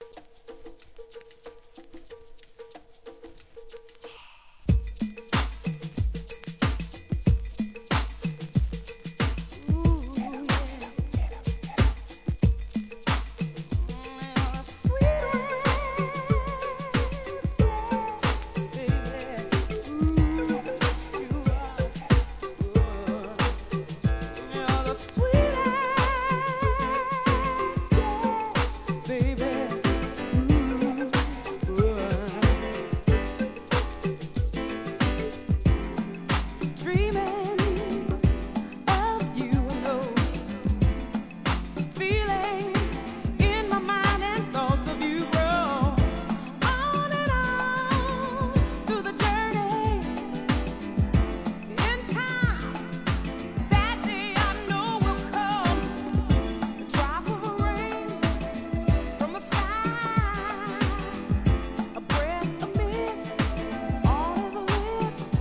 ¥ 880 税込 関連カテゴリ SOUL/FUNK/etc...